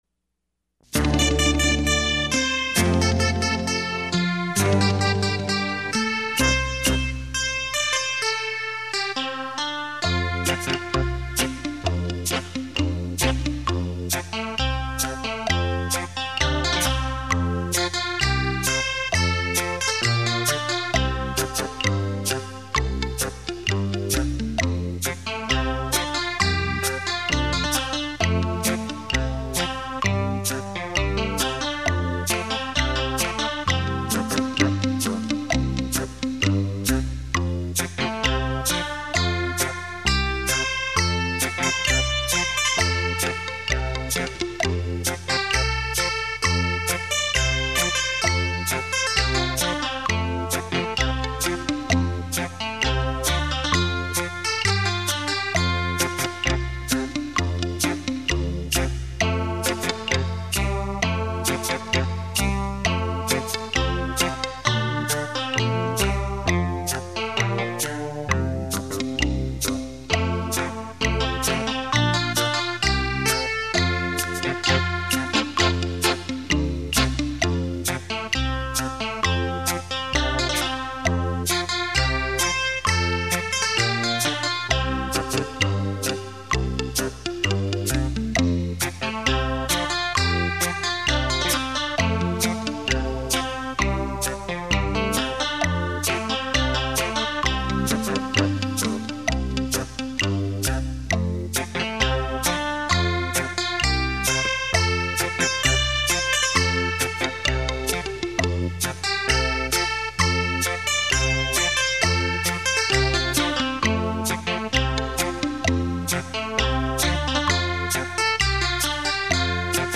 立体音声 环绕效果
音响测试专业DEMO碟 让您仿如置身现场的震撼感受